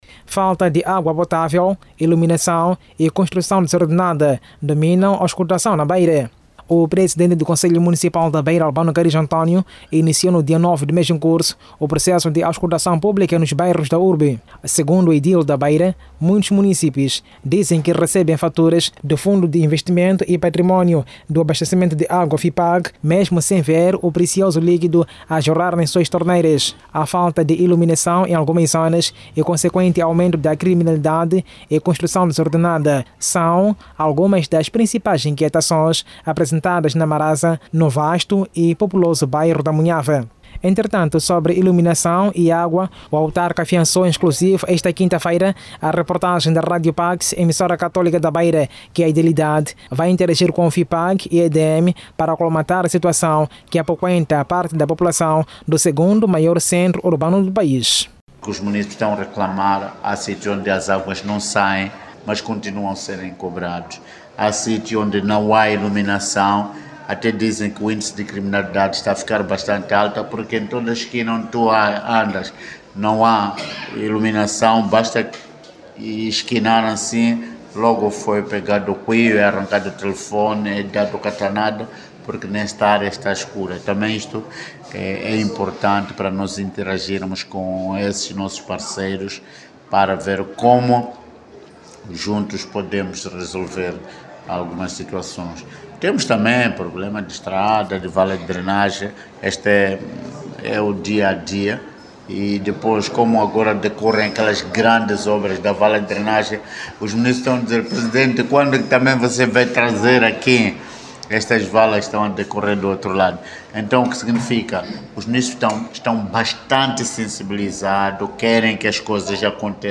Satisfeitos estavam os munícipes que aceitaram falar ao nosso microfone, elogiaram a iniciativa da edilidade e esperam que suas preocupações sejam resolvidas.
O presidente do Conselho Municipal da Beira, aproveitou o microfone da Rádio Pax, para agradecer os munícipes e exortá-los a expor suas preocupações exatamente nos próprios bairros.